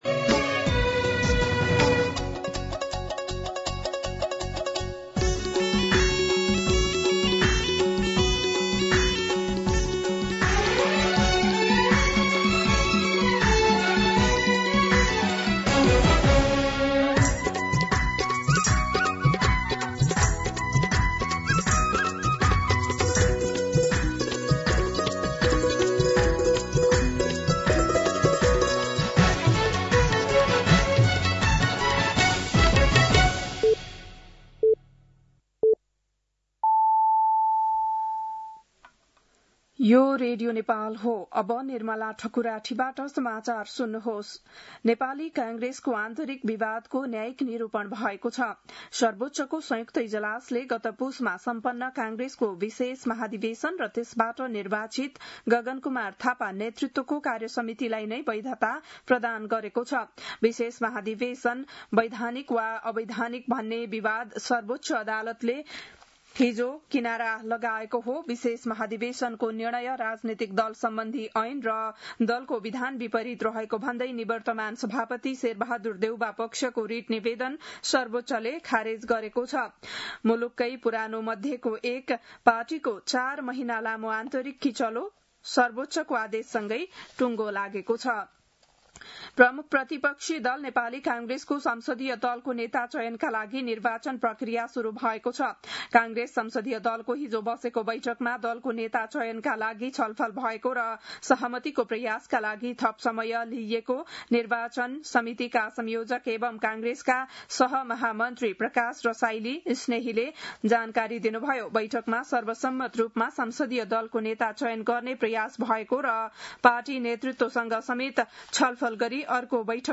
बिहान ११ बजेको नेपाली समाचार : ५ वैशाख , २०८३
11-am-Nepali-News-1.mp3